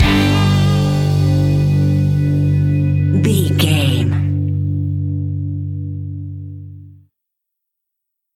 One Movement Hit.
Epic / Action
Ionian/Major
hard rock
heavy drums
distorted guitars
hammond organ